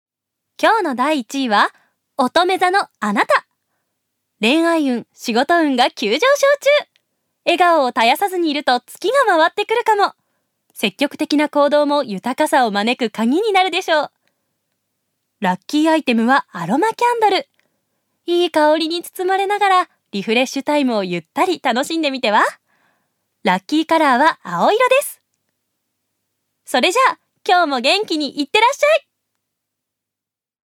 預かり：女性
ナレーション２